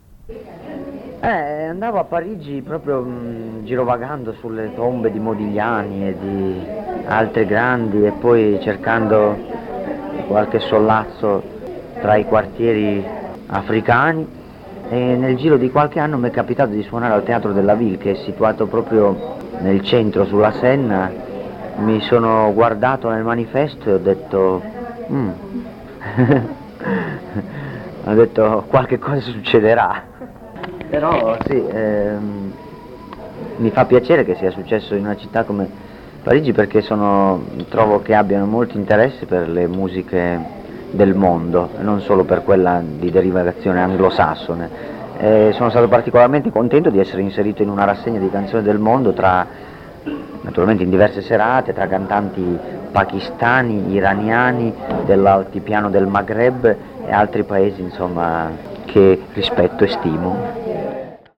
Dichiarazioni raccolte in occasione del concerto di Moncalvo (26 marzo 1996), quando l’organizzazione della serata di Ricaldone era ai suoi inizi.